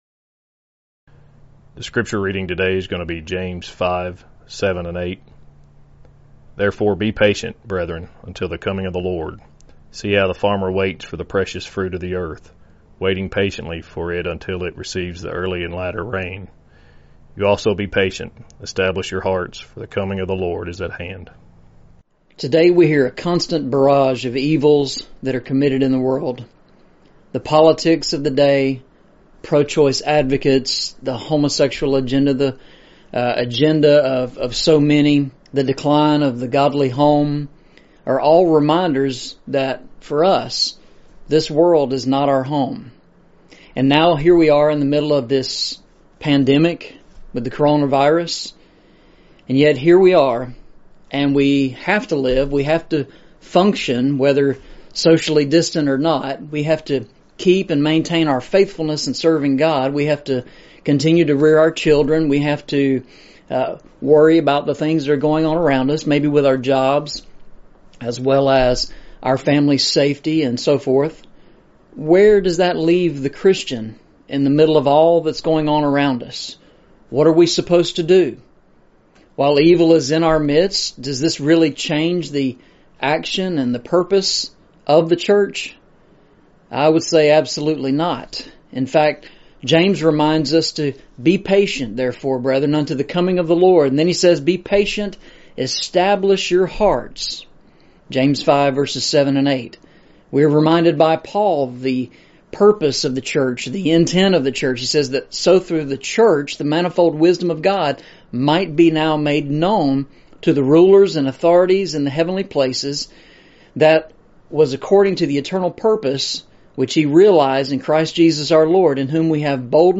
Series: Eastside Sermons
Eastside Sermons Service Type: Sunday Morning Preacher